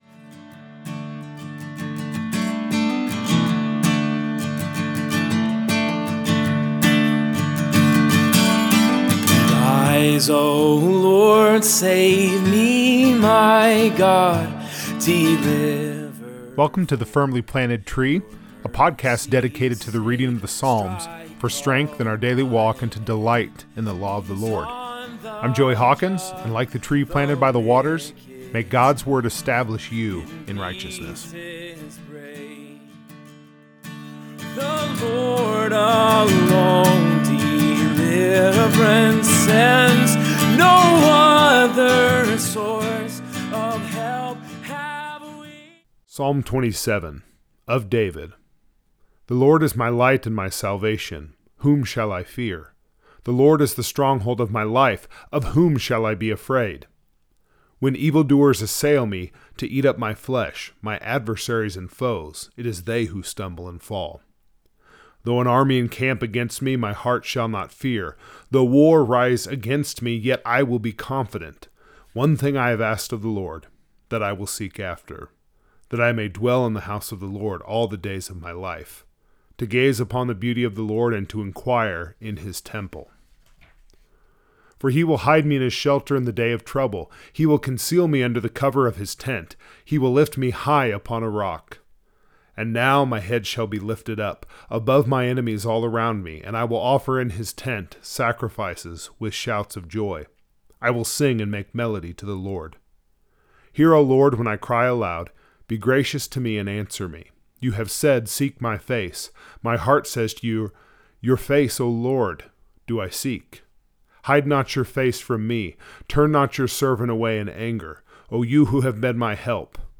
What made David, well, David? In this episode, we read and reflect on Psalm 27 and what makes David the “man after God’s own heart.”